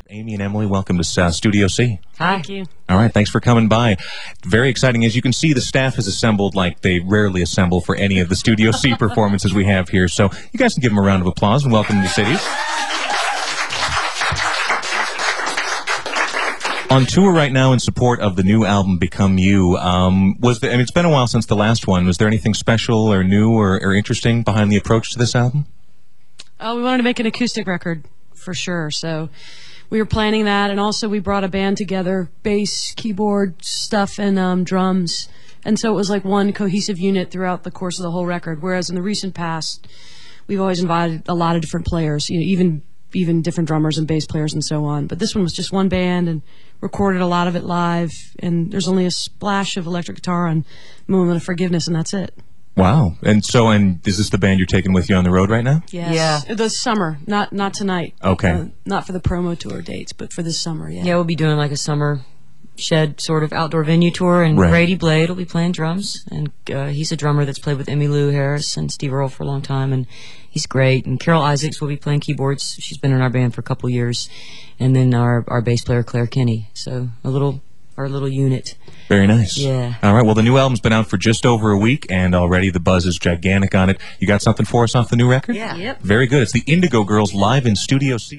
02. interview (1:28)